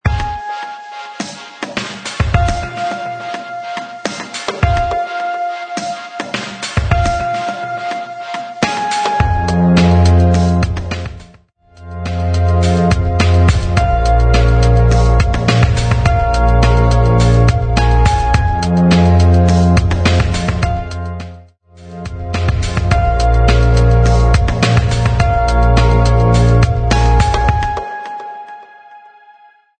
105 BPM
Smooth Electronic